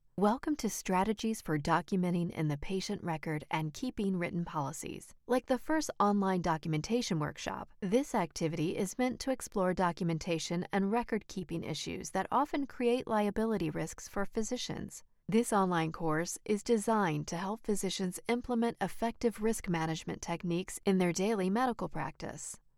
• E-Learning Sample
Broadcast Quality LA Vocal Booth
• Neumann TLM 103
• Sennheiser MKH 416